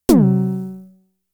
Snare (11).WAV